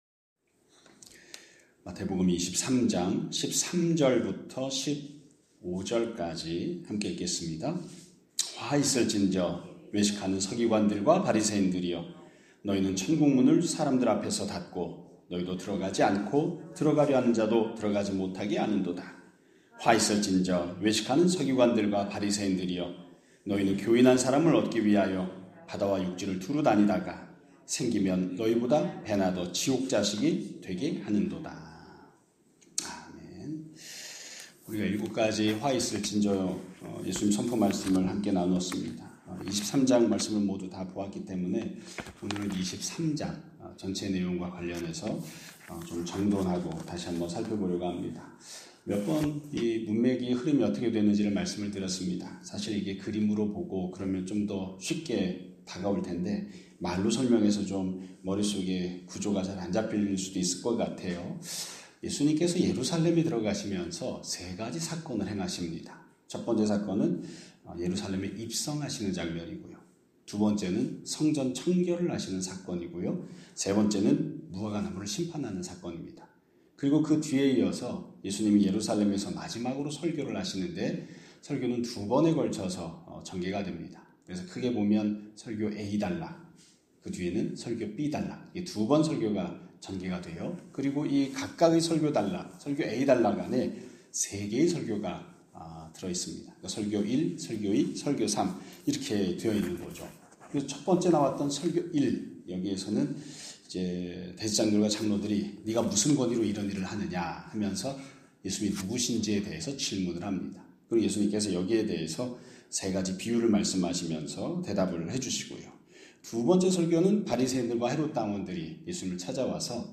2026년 3월 5일 (목요일) <아침예배> 설교입니다.